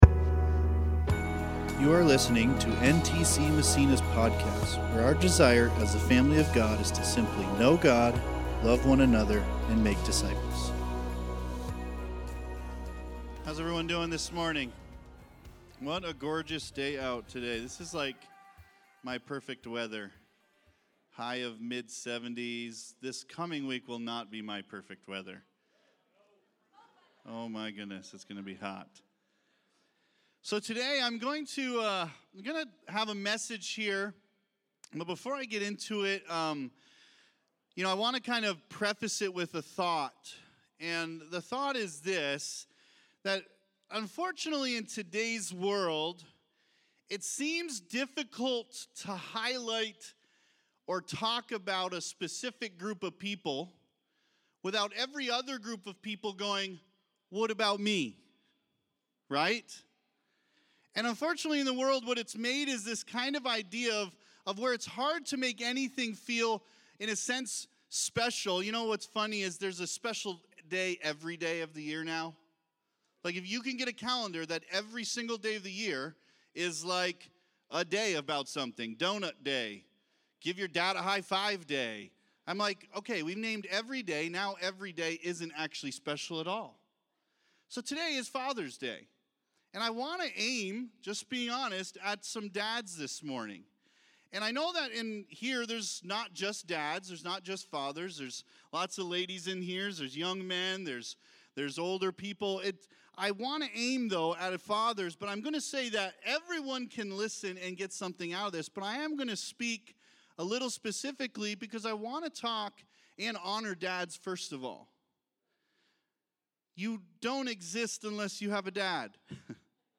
2024 Living Grateful Preacher